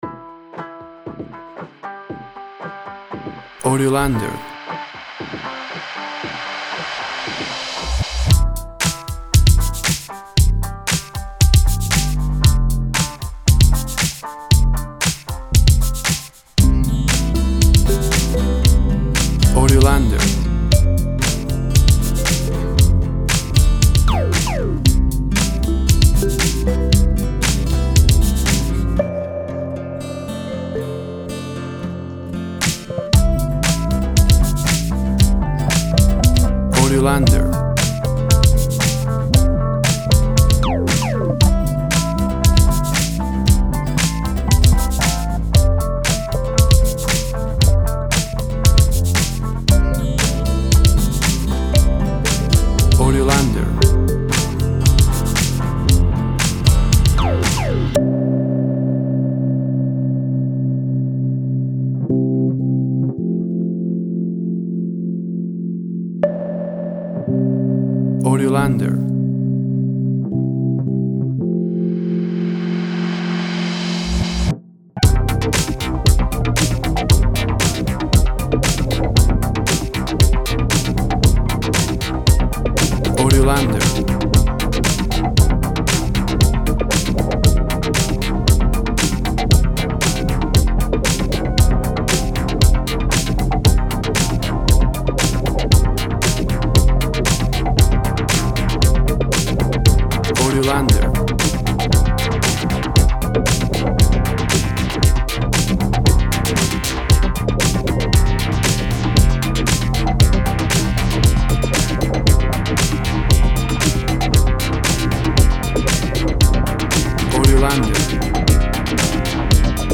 Fresh music, Dance.
Tempo (BPM) 116